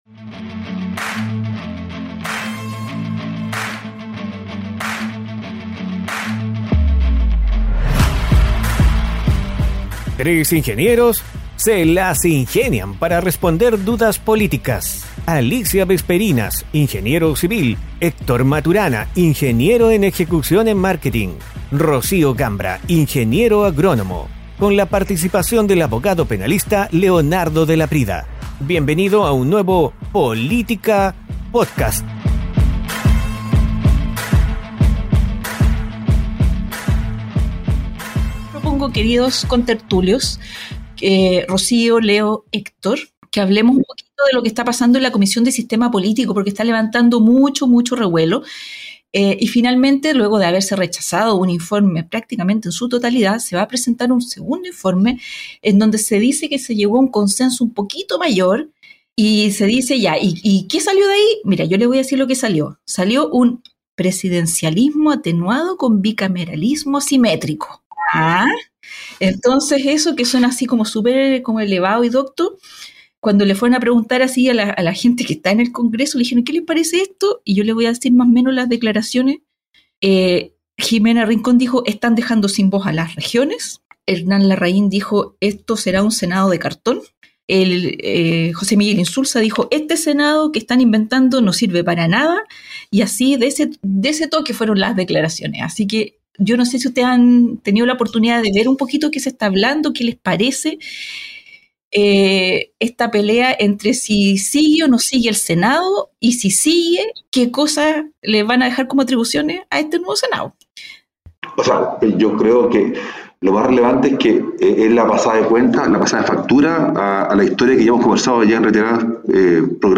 Tres ingenieros se las ingenian para responder dudas políticas